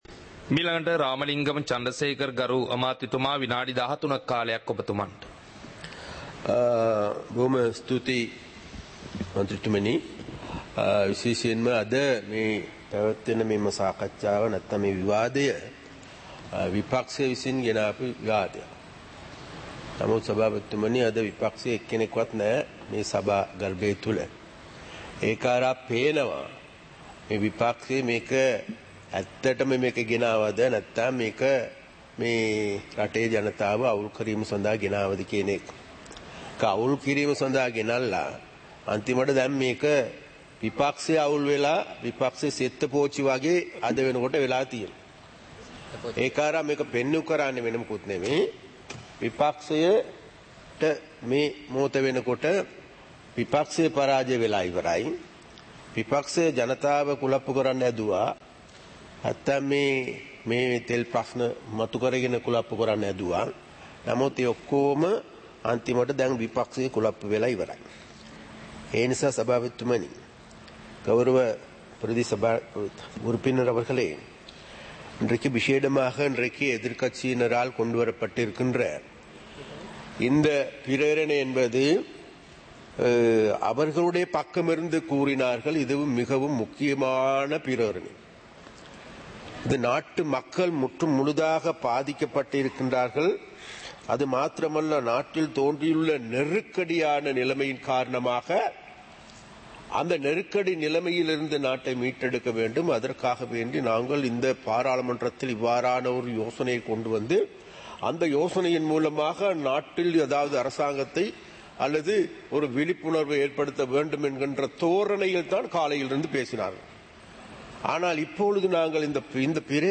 சபை நடவடிக்கைமுறை (2026-03-19)